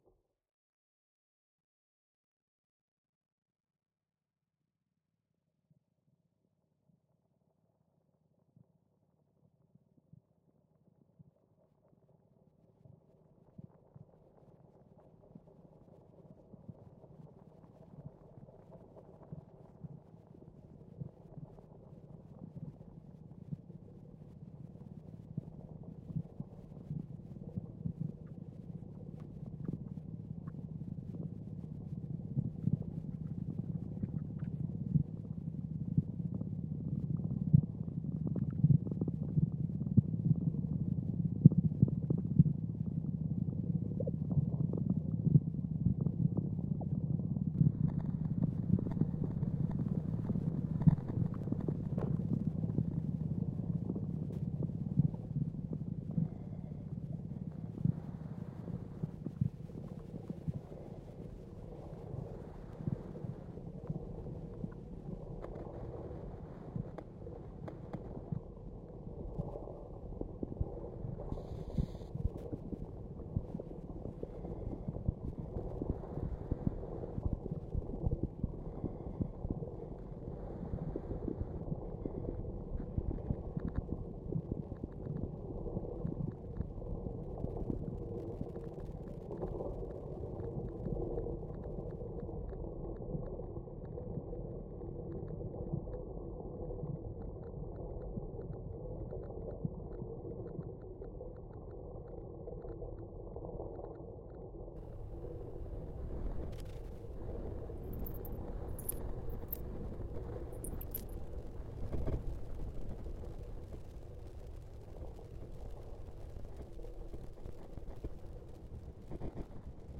Contacts mics on Koggala Beach